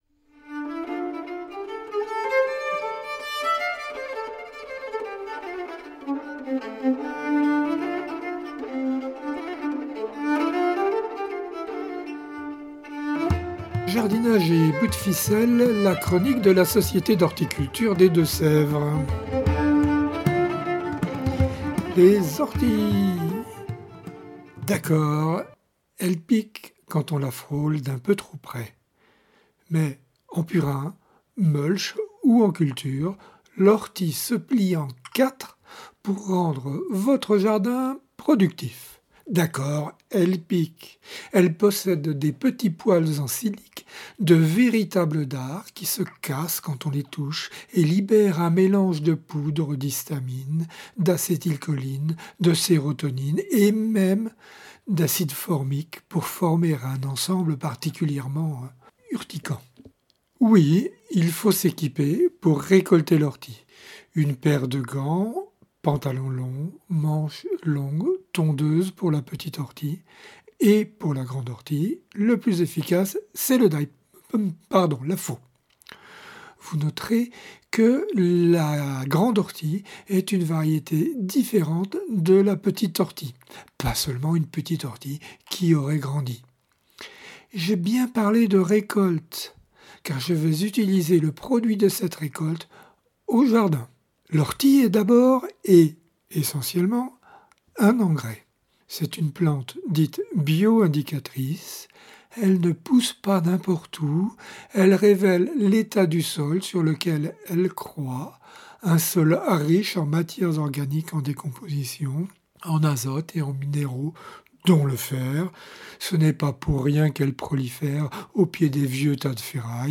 (Ces chroniques sont diffusées chaque semaine sur les radios D4B et Pigouille Radio)